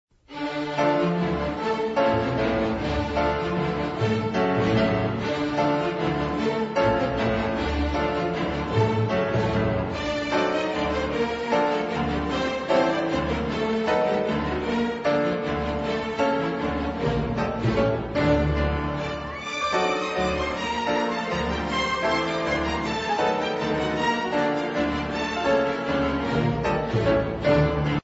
برای پیانو و ارکستر